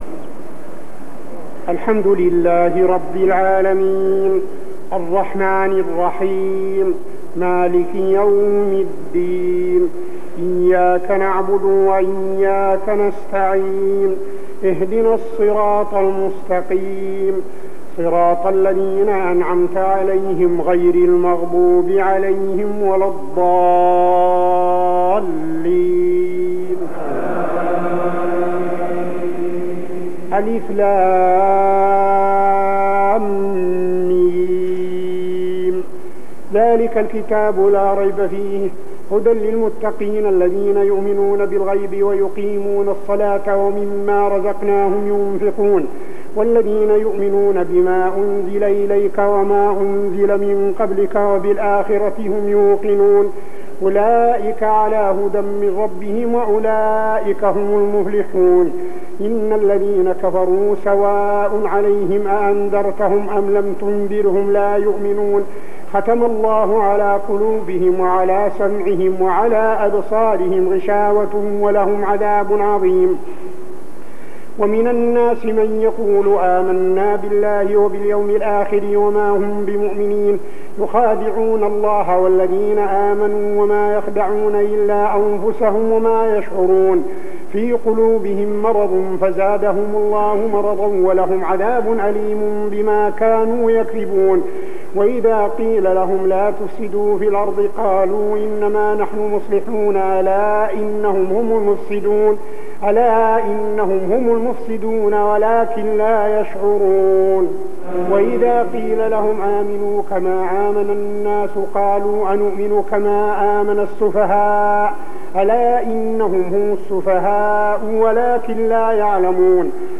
صلاة التراويح ( تقريباً عام 1401هـ ) سورتي الفاتحة كاملة و البقرة 1-59 | Tarawih prayer Surah Al-Fatihah and Al-Baqarah > تراويح الحرم النبوي عام 1401 🕌 > التراويح - تلاوات الحرمين